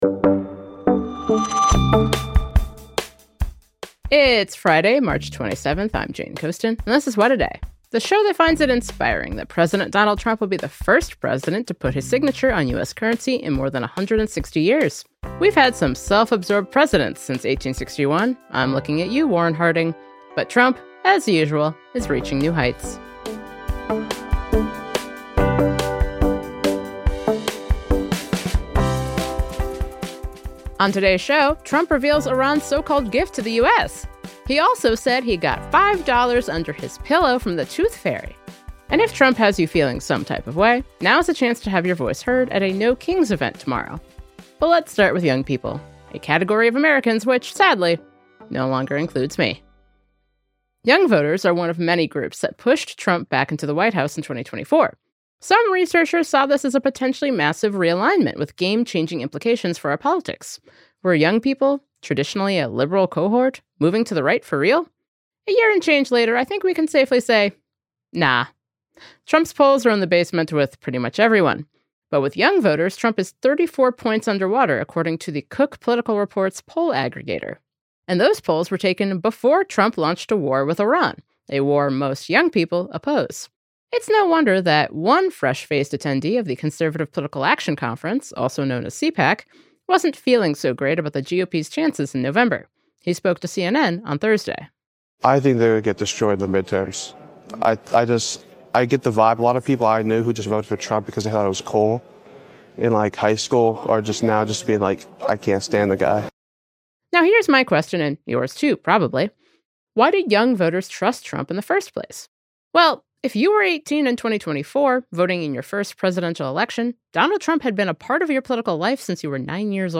So what should Democrats do to reach young voters where they're at? To find out, we spoke to Florida Democratic Rep. Maxwell Frost.